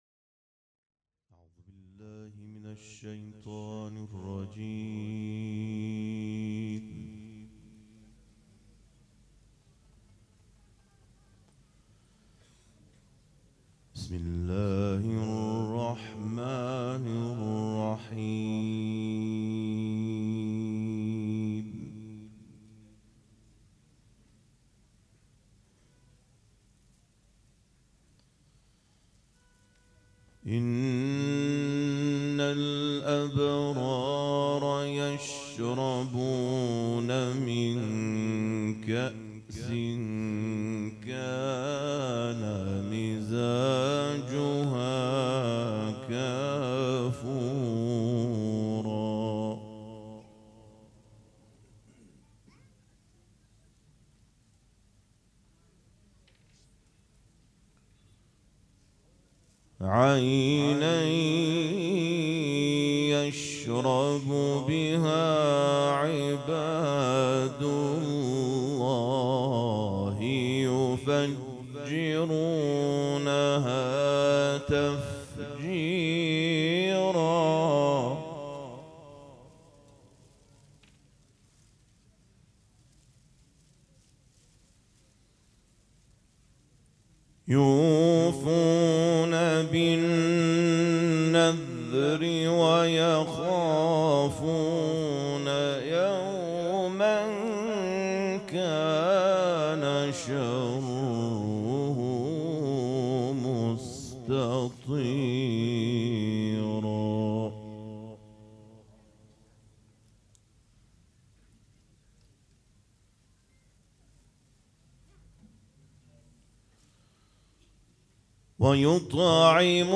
سبک اثــر قرائت قرآن
مراسم جشن ولادت سرداران کربلا (شب دوم)